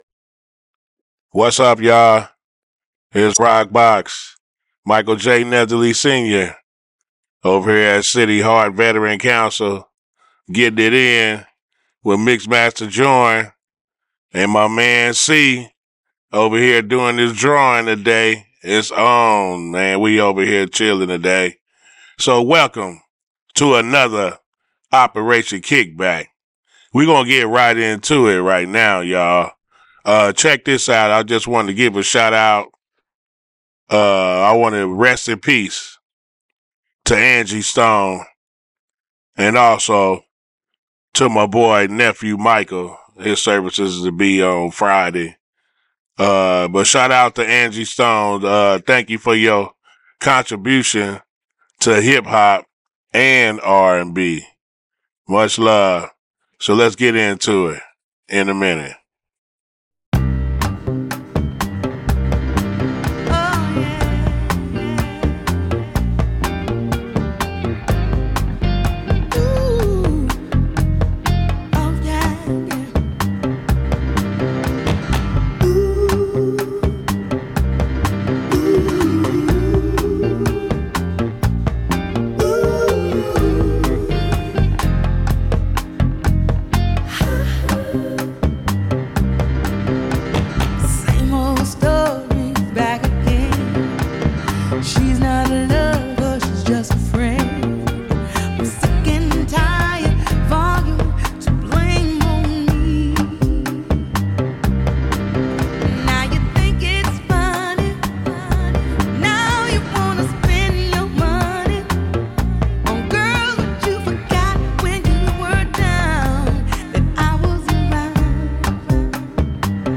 This episode of Voices from THE HUB FT Moment In Between and Operation Kickback aired live on CityHeART Radio on Tuesday Mar. 04 at 10am. This episode featured a mental health segment focused on springtime – and how to care for your mental wellness as the seasons change. Moment in Between also shares the airwaves with Operation Kickback – with another exciting show from them full of awesome music for this episode!